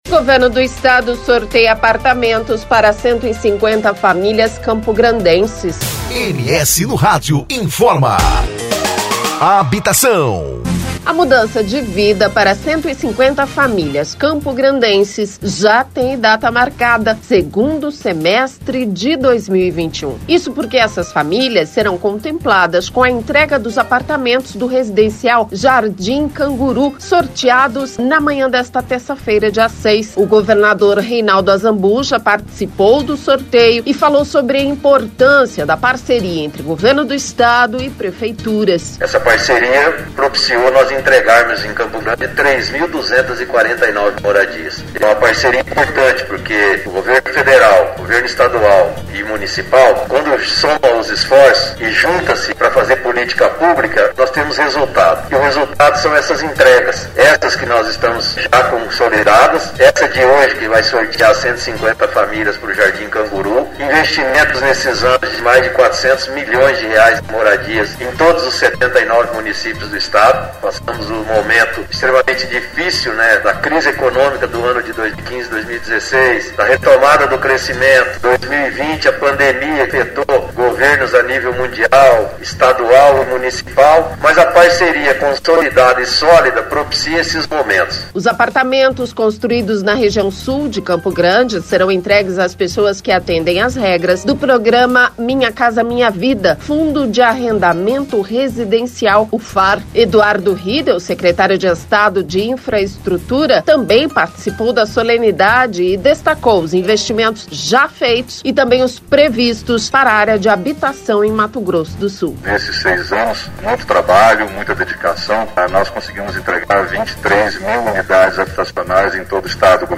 O governador Reinaldo Azambuja participou do sorteio e falou sobre a importância da parceria entre Governo Estadual e Prefeituras.
Eduardo Riedel, secretário de estado de infraestrutura, também participou da solenidade e destacou os investimentos já feitos, e também os previstos para área de habitação em Mato Grosso do Sul.